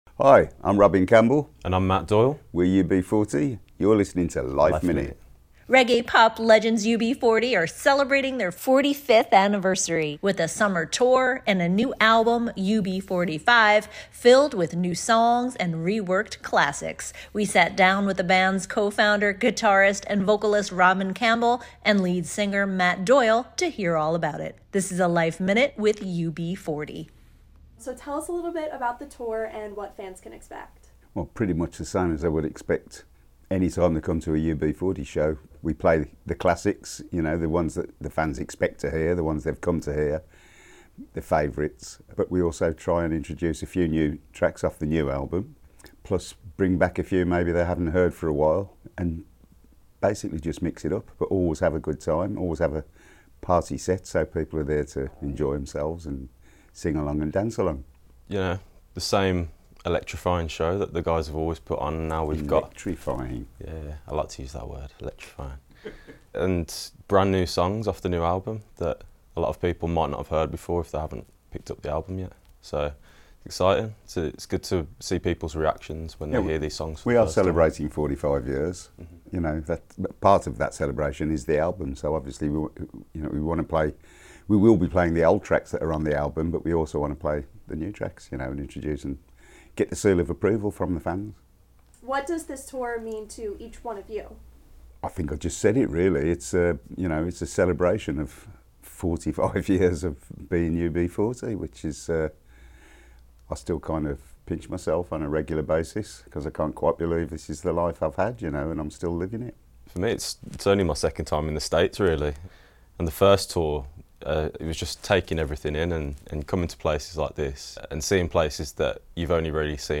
The reggae/pop legends are celebrating their 45th Anniversary with a summer tour and a new album, UB45 , filled with new songs and reworked classics. We sat down with guitarist, vocalist, and band co-founder Robin Campbell and current lead singer, Matt Doyle, who joined the band in 2021, taking over for a retired Duncan Campbell, to hear all about it.